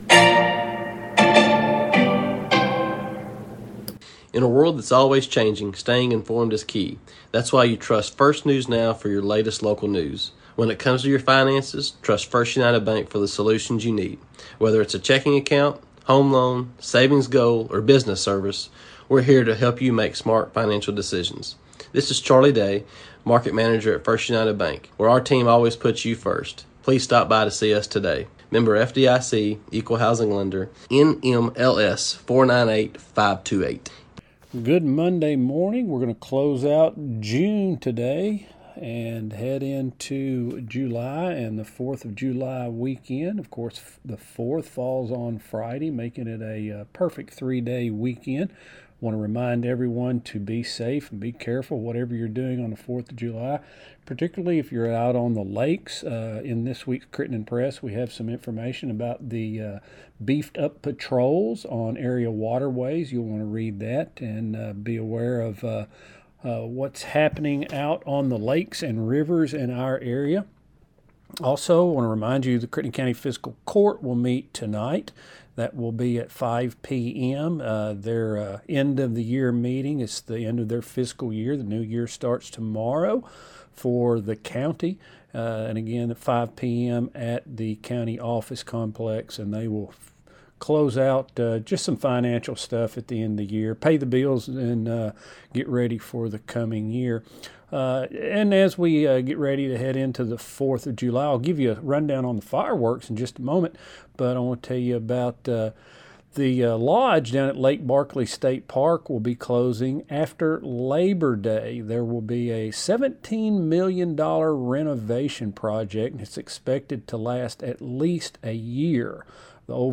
News | Sports | Interviews